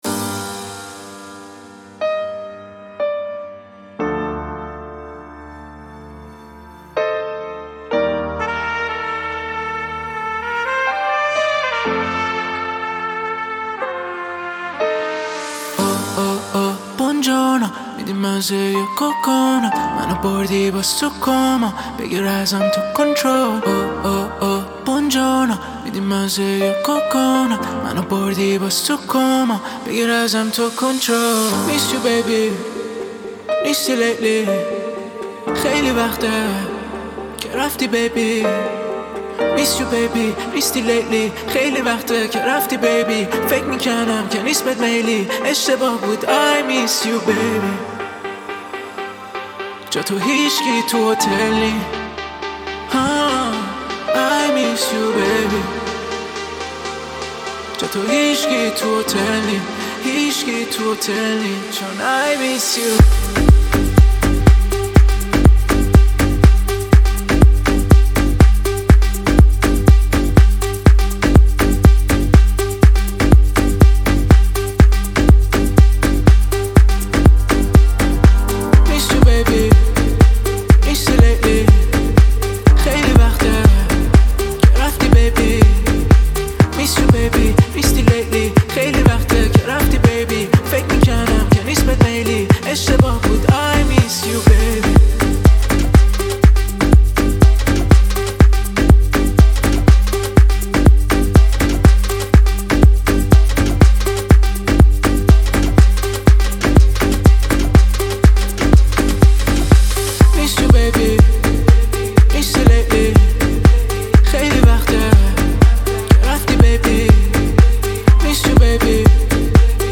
با ریتم دلنشین
موسیقی پاپ و رپ فارسی